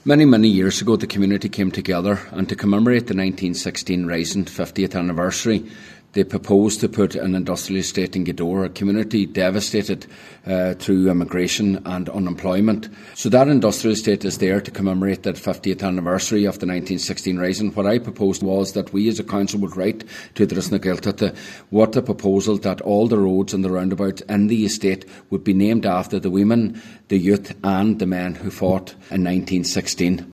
Cllr McGiolla Easbuig says it would be a fitting Tribute, and in keeping with the estate’s history………….